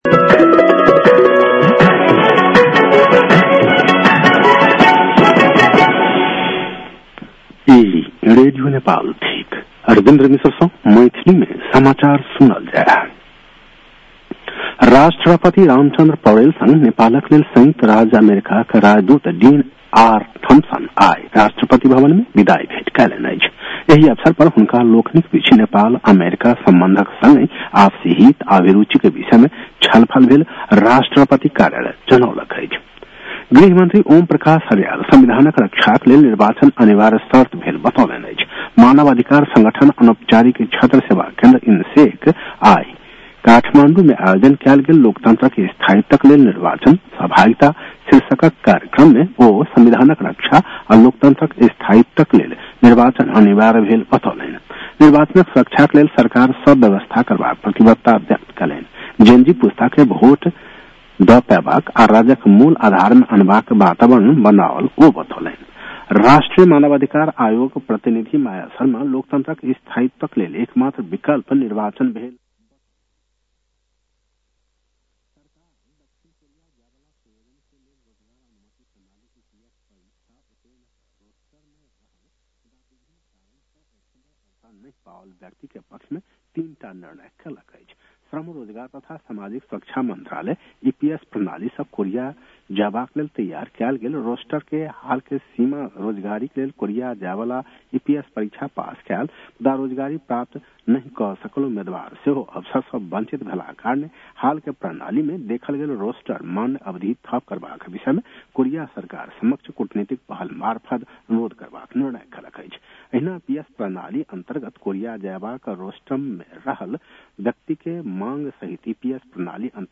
मैथिली भाषामा समाचार : २४ पुष , २०८२
6-pm-maithali-news-9-24.mp3